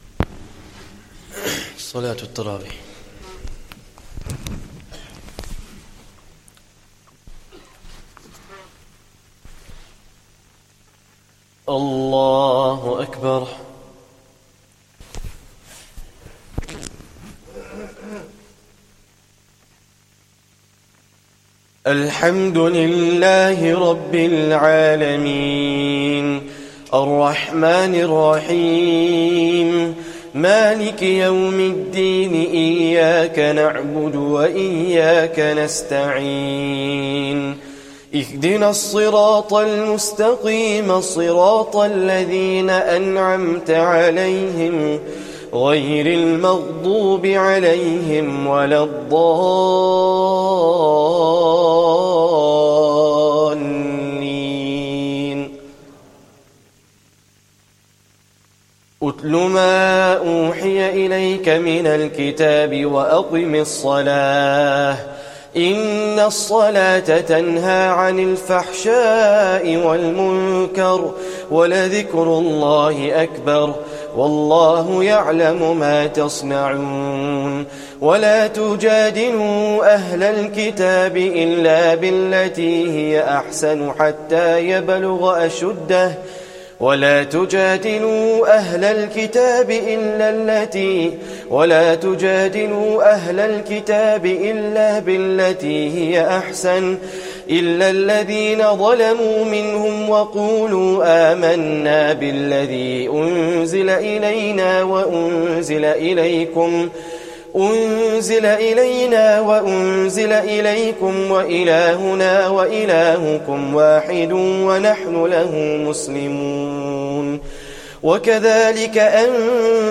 Taraweeh Prayer 19th Ramadhan